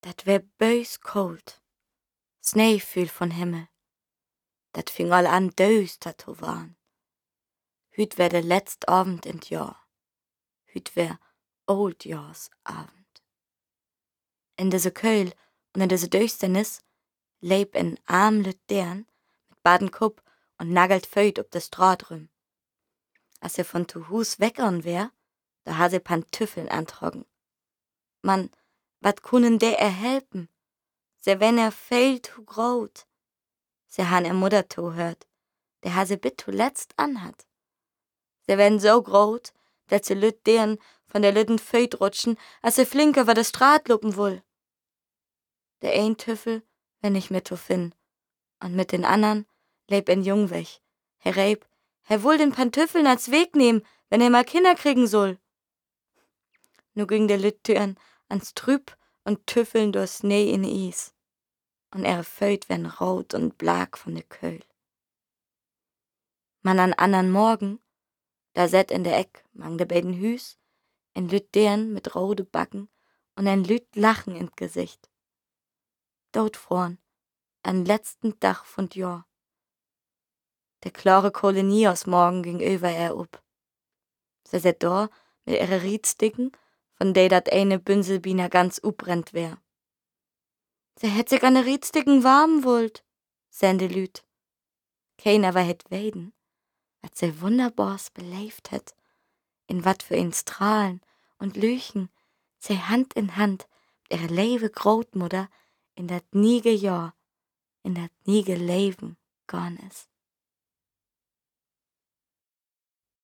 hell, fein, zart, markant, sehr variabel
Jung (18-30)
Audio Drama (Hörspiel), Tale (Erzählung)
Norddeutsch